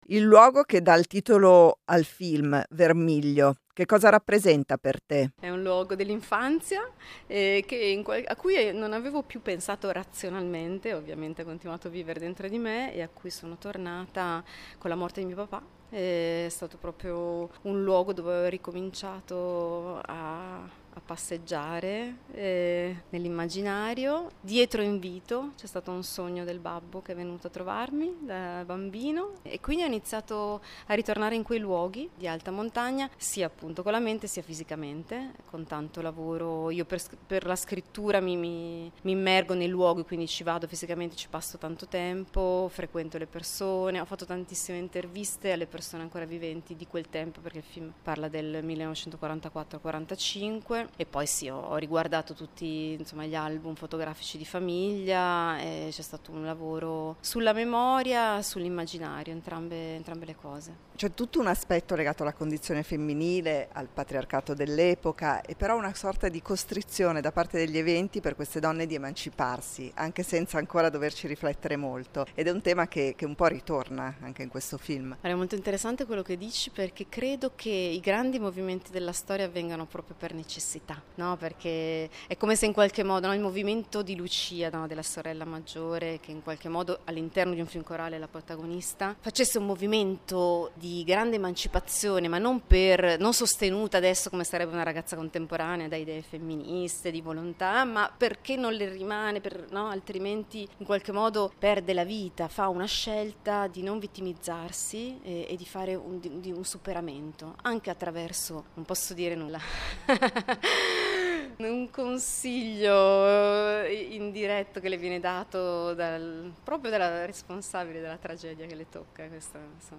Intervista a Maura Delpero regista di "Vermiglio"